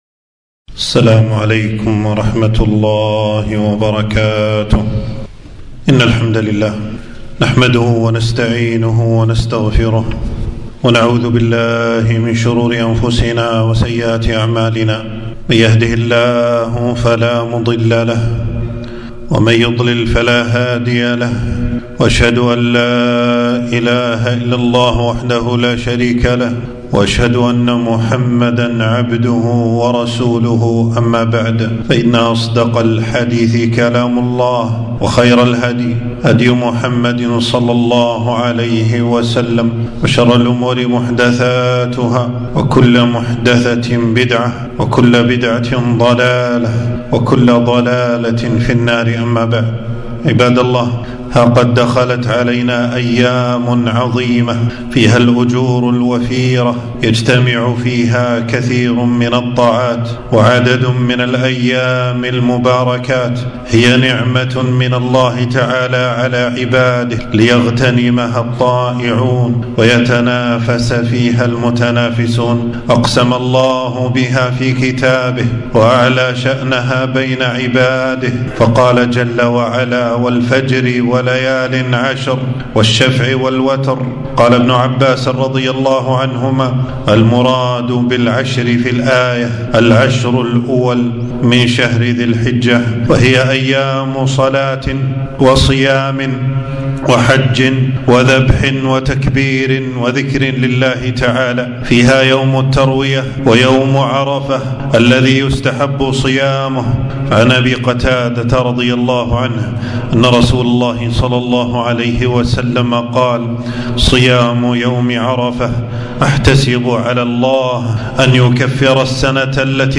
خطبة - أفضل أيام الدنيا